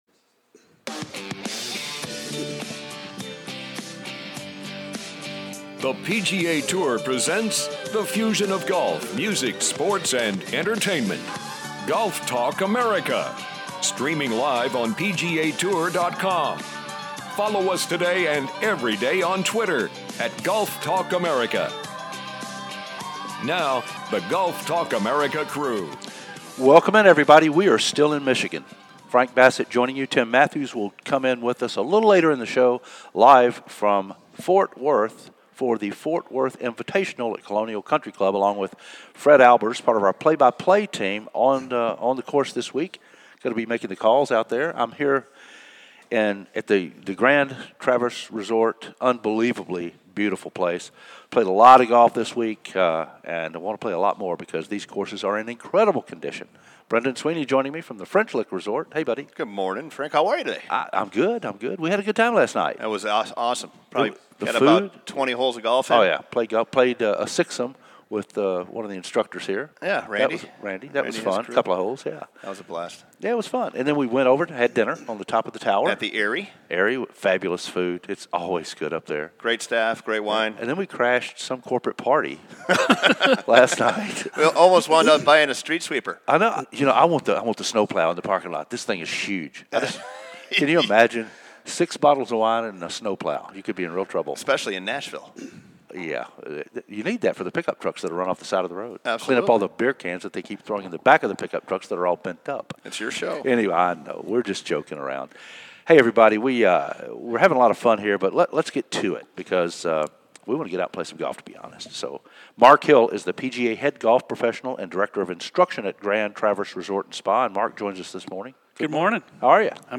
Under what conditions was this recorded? "LIVE" from The Grand Traverse Resort & from The Ft. Worth Invitational with Stories of Pure Michigan, Caddy Antics & Ben Hogan....What could be better?